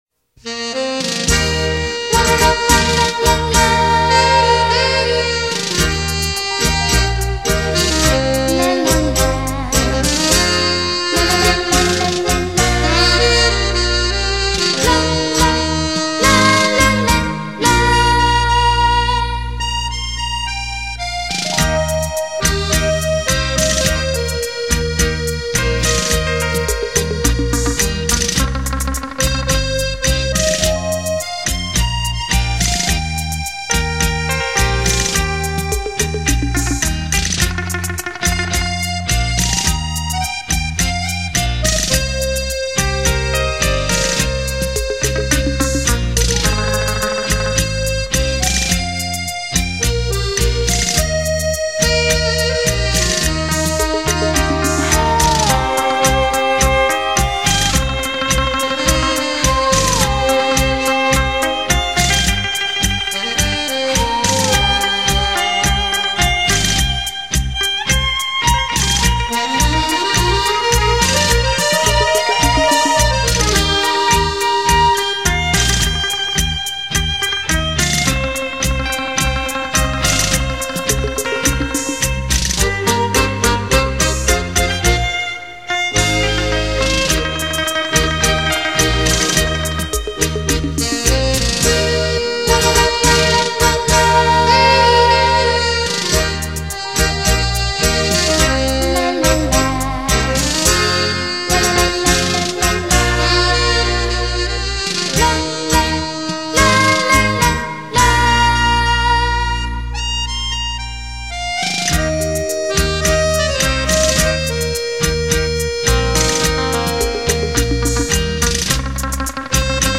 兴来出品，舞曲珍品。　　　　　　　　.
探戈至尊，曲曲动听。　　　　　　　　.
优美的探戈旋律让人“醉”入其中．．．　.